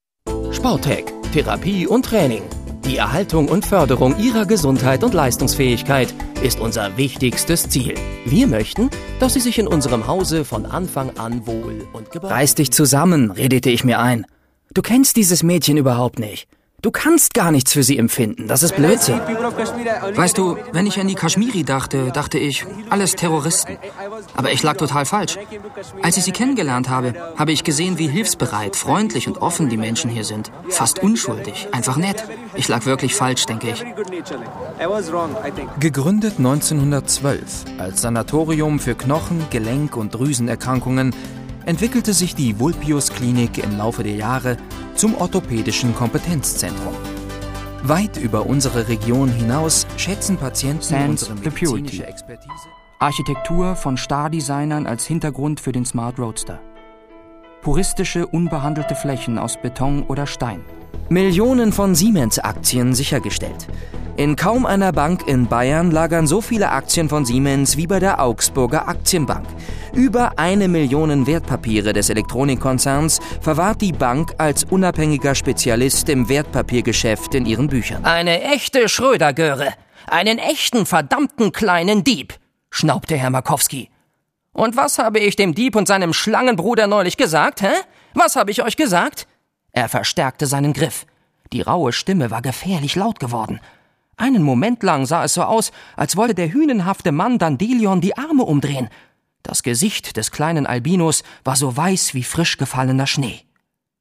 Die junge Stimme mit Erfahrung.
Im Bereich Off / Voice Over biete ich Ihnen eine angenehme Stimmfärbung, mal abseits der sonst typischen tief / markanten Erzählstimme.
Junge Stimme, Sprecher für: Werbung, Audio Ads, Dokumentation, E-Learning, Zeichentrick, Jingles, Lieder, Synchron, etc.
deutsch
Sprechprobe: Industrie (Muttersprache):